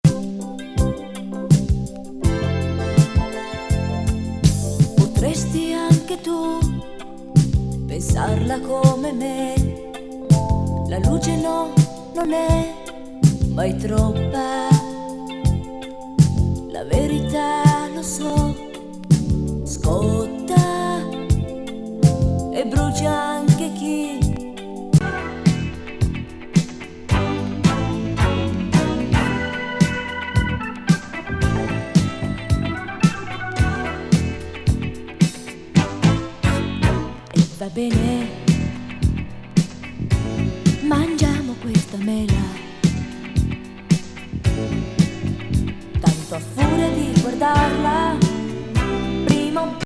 イタリアン女性シンガー82年作。
軽快メロウ・ディスコ